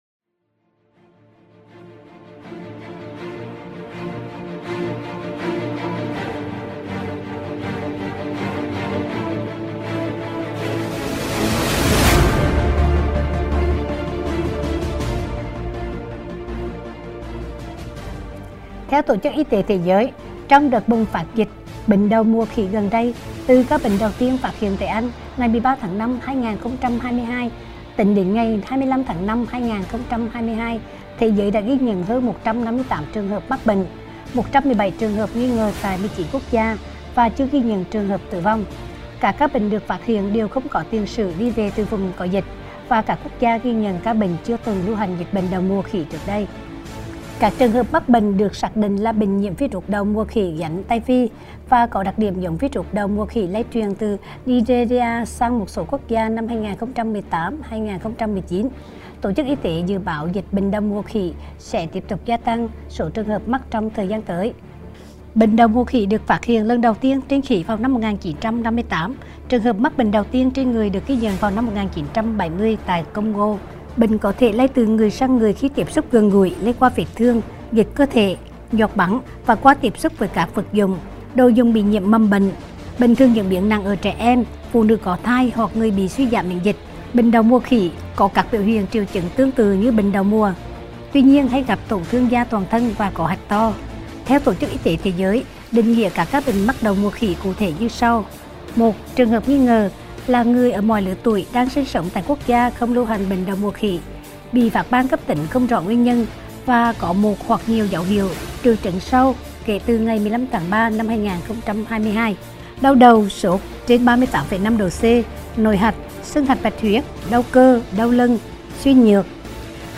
Phát thanh Bệnh đậu mùa khỉ